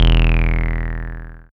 78.06 BASS.wav